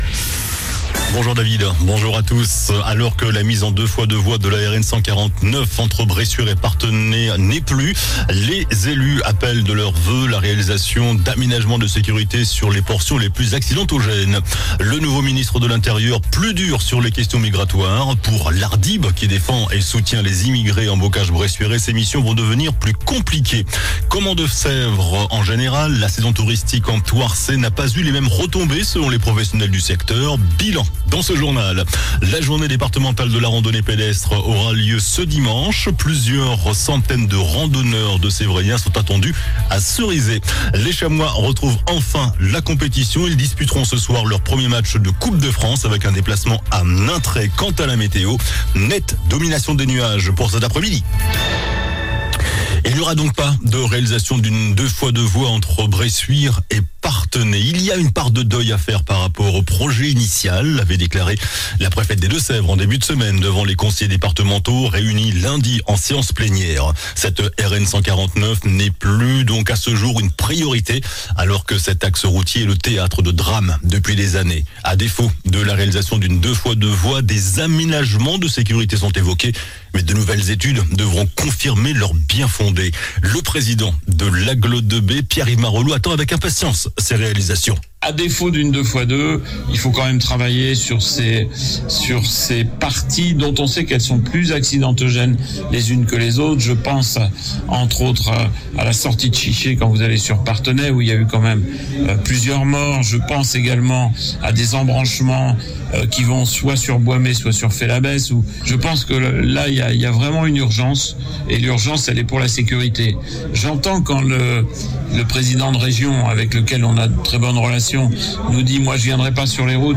JOURNAL DU MERCREDI 25 SEPTEMBRE ( MIDI )